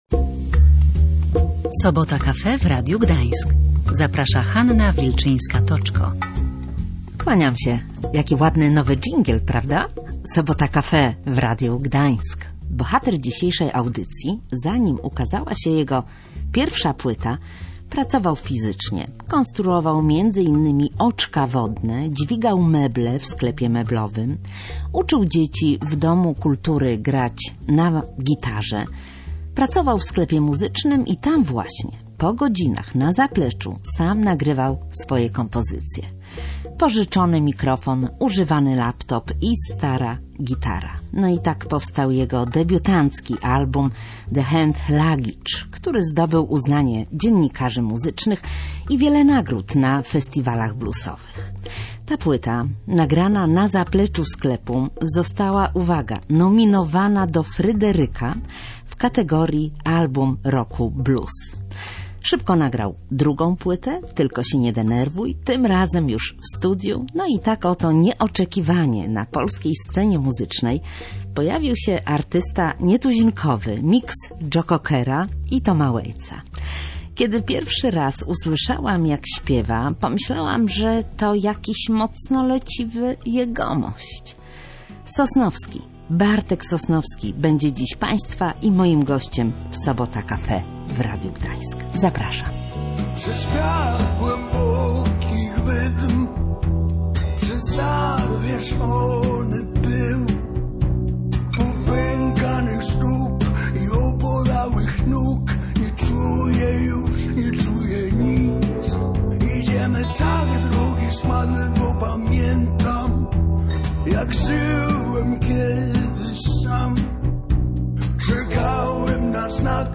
Niektórzy mówią, że jego głos brzmi jak papier ścierny.